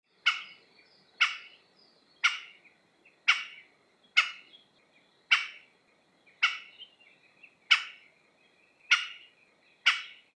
アオゲラ｜日本の鳥百科｜サントリーの愛鳥活動
「日本の鳥百科」アオゲラの紹介です（鳴き声あり）。